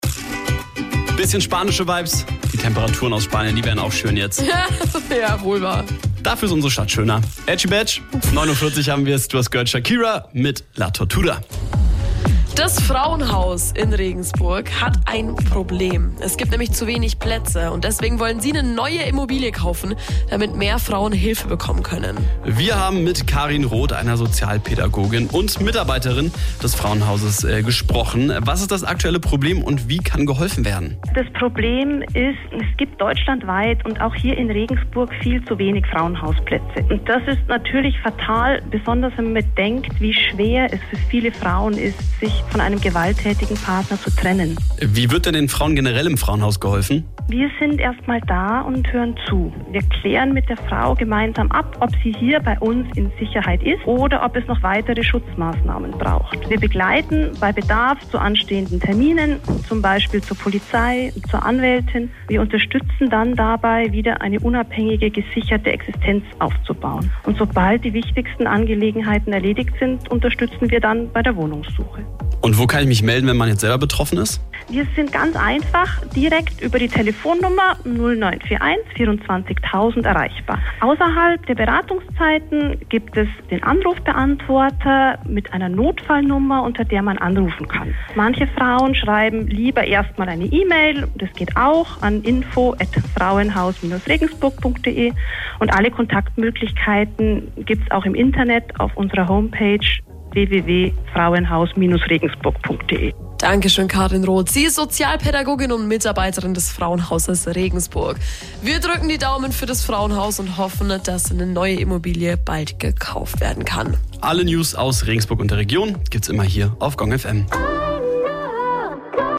Radiobetrag von Gong FM
FrauenhausAircheck_gongfm.mp3